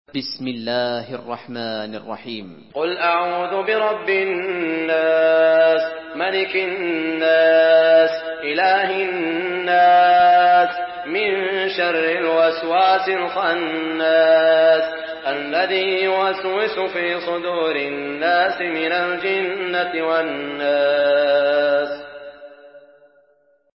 Surah Nas MP3 in the Voice of Saud Al Shuraim in Hafs Narration
Murattal Hafs An Asim